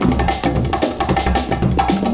112PERCS03.wav